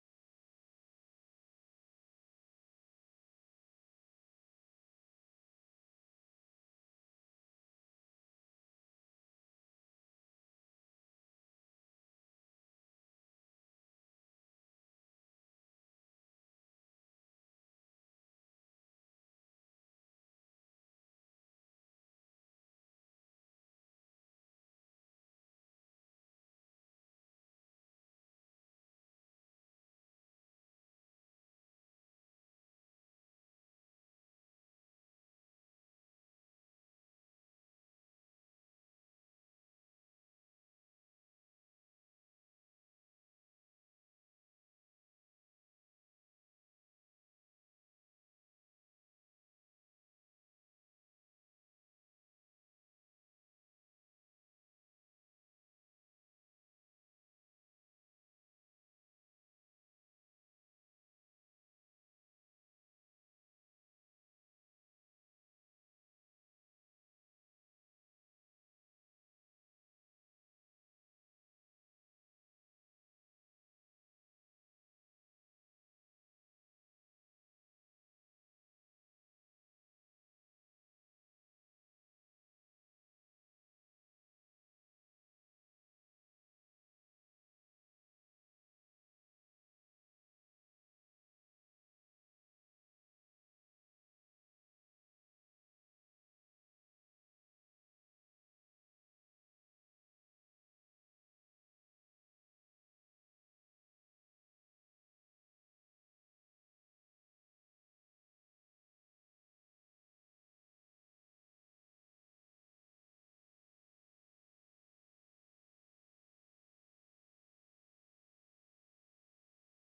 Beyond The Ice Palace II Pt. 2! (No Audio)
Game: Beyond The Ice Palace II Storybird Studio) Sorry about this, simple error with OBS.